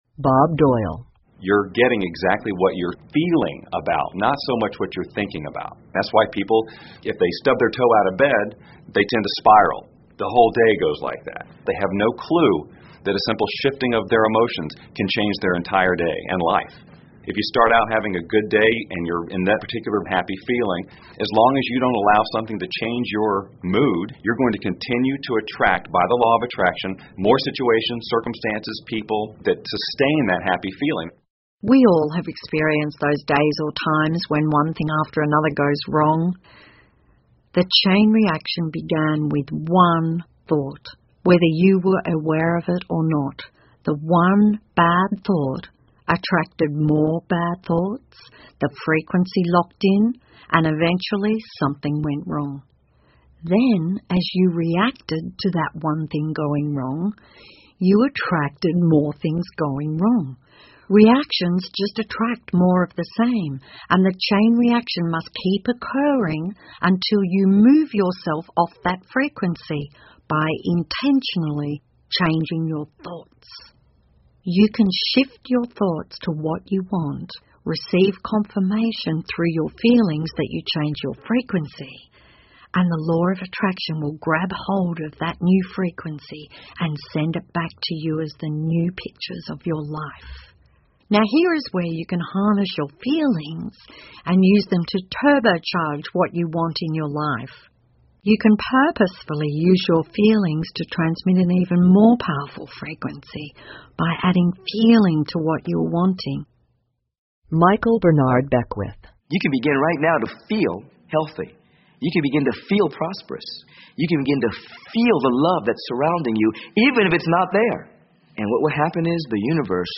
有声畅销书-秘密 1.18 听力文件下载—在线英语听力室